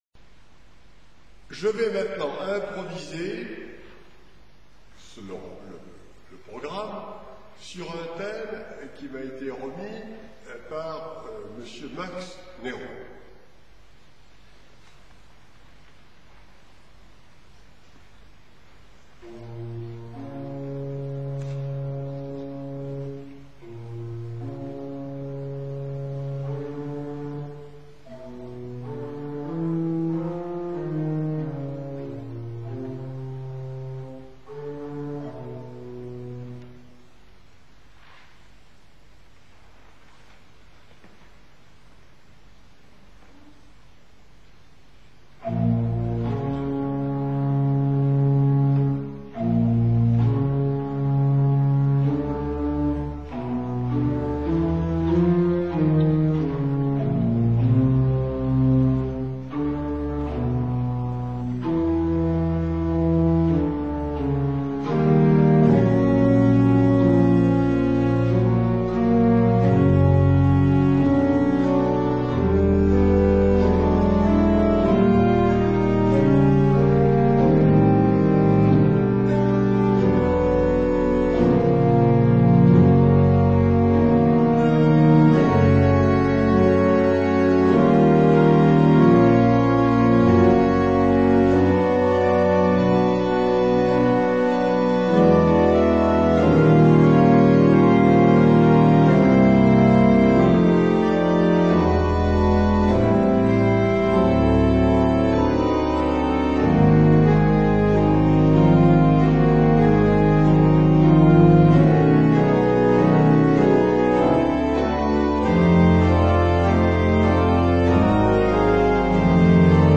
� l�orgue de l�Auditorium de Saint-Omer (Pas-de-Calais), le 20 f�vrier 1981 : improvisation
Litaize_improvisation.mp3